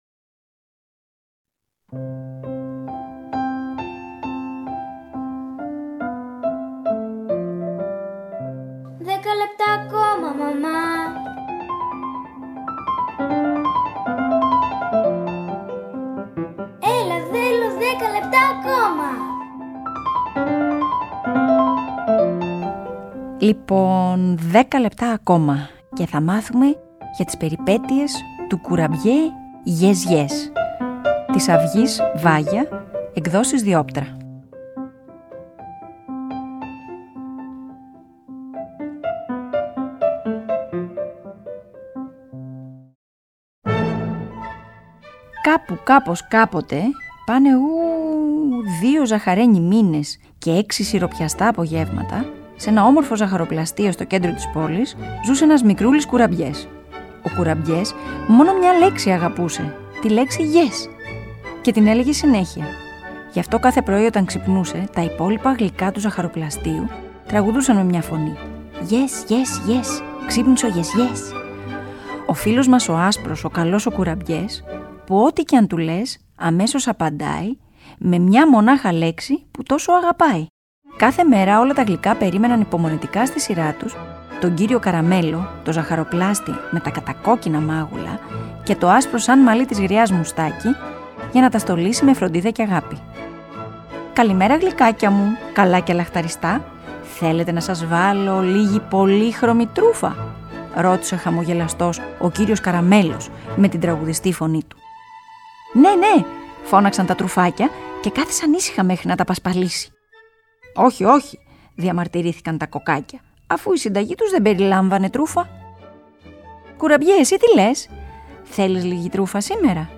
Αφήγηση